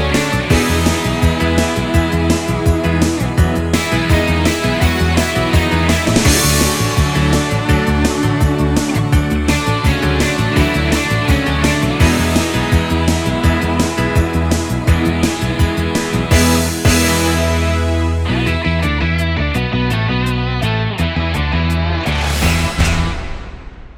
6 Semitones Down Pop (1980s) 4:11 Buy £1.50